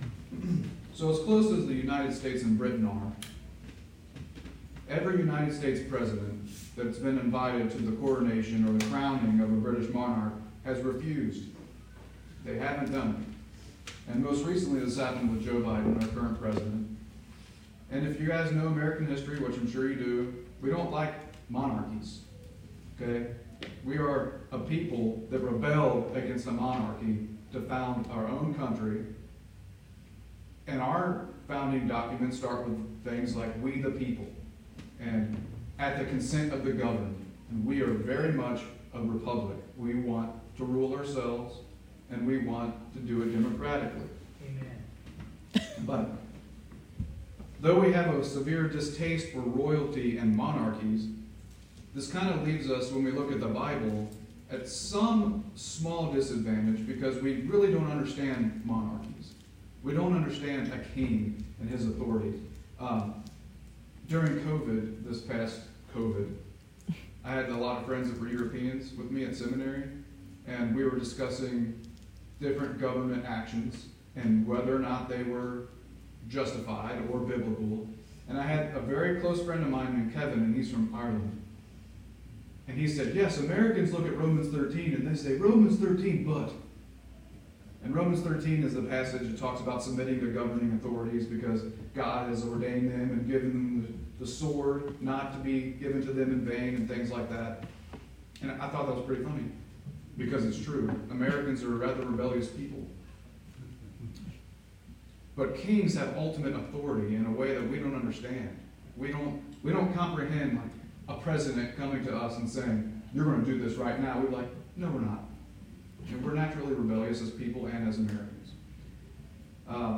Disclaimer: This sermon was recorded impromptu with low quality sound and background noise.